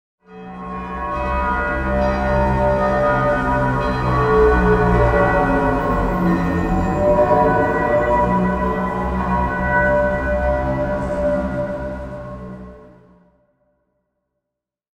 Spooky-haunted-halloween-bells-sound-effect.mp3